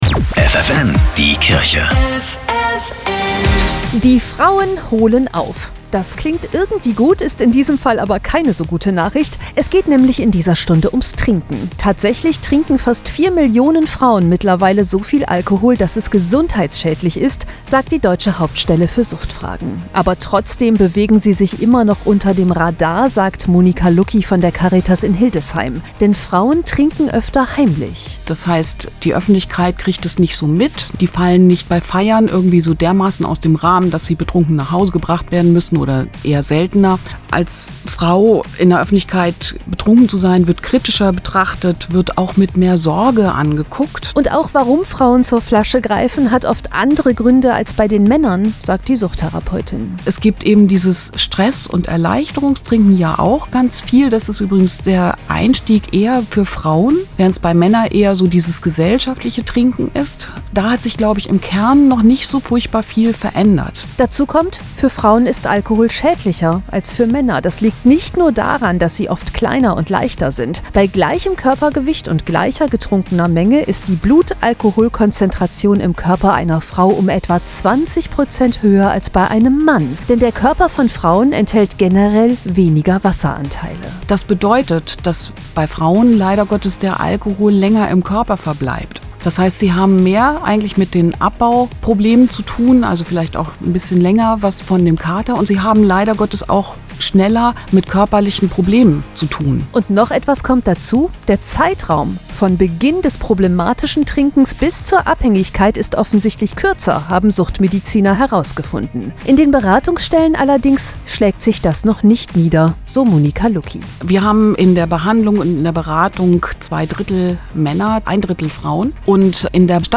Radiobeitrag (Radio ffn) Frauen und Alkohol Teil 2